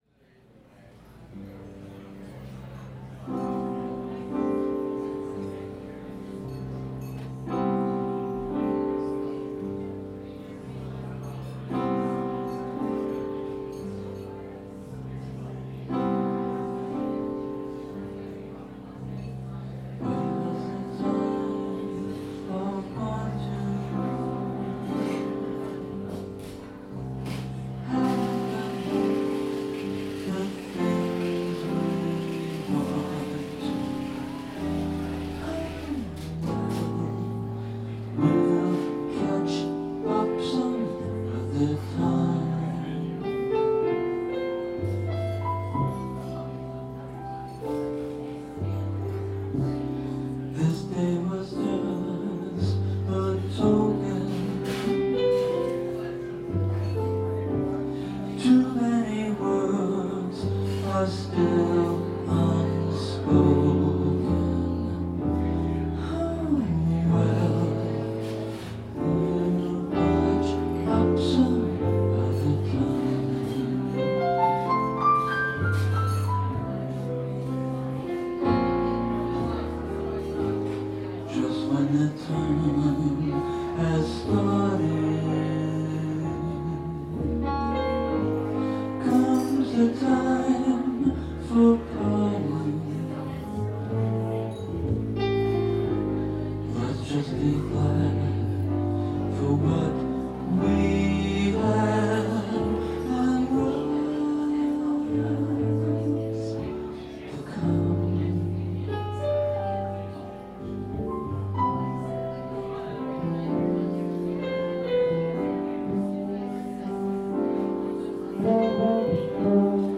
voice and quartet